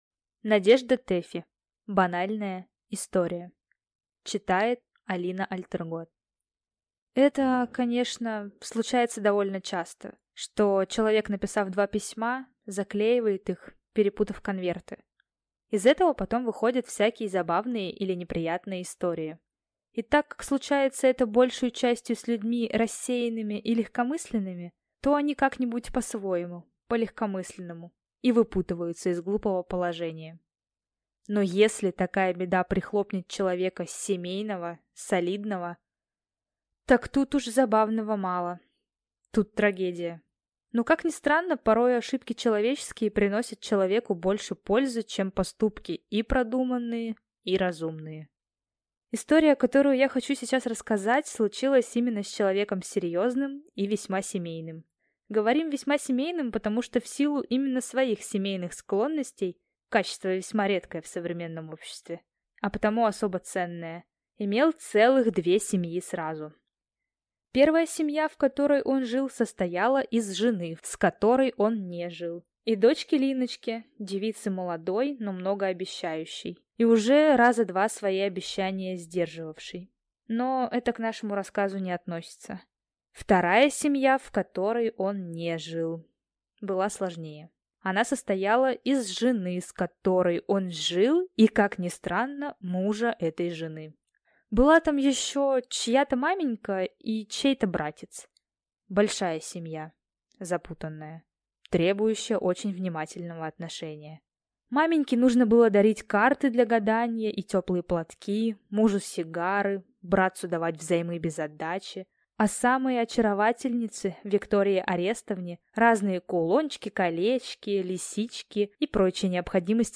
Скачать, слушать онлайн аудиокнигу Банальная история автора Тэффи Надежда